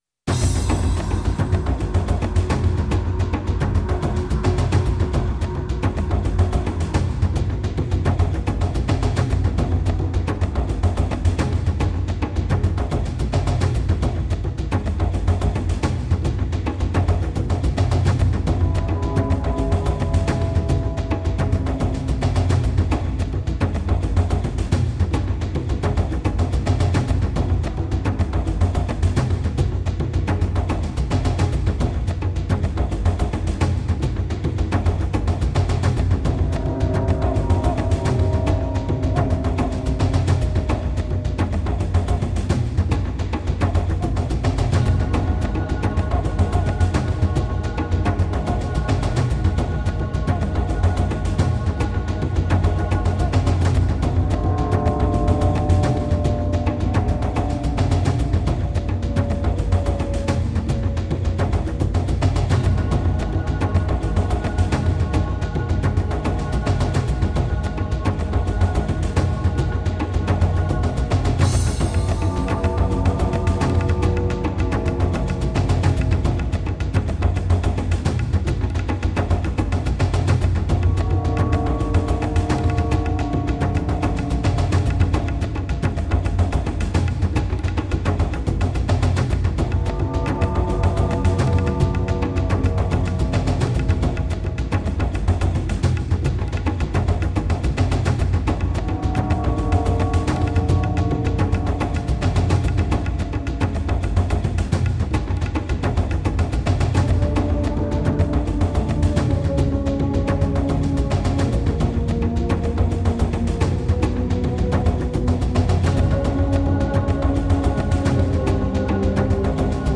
soundtrack/mood music like accompaniment